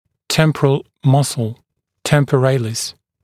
[‘tempərəl ‘mʌsl], [ˌtempəˈreɪlɪs][ˌтэмпэˈрэйлис]височная мышца